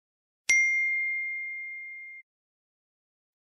Nada Notif Ting iPhone
Genre: Nada notifikasi Tag: nada dering iPhone , nada notifikasi , nada notifikasi iPhone Ukuran file: 476 KB Dilihat: 8953 Views / 801 Downloads Detail: Yo bro, lagi cari nada notif yang bikin iPhone lo stand out?
Suaranya ikonik, pendek tapi ngena, cocok buat nada dering WA atau notif aplikasi lain.
nada-notifikasi-ting-iphone.mp3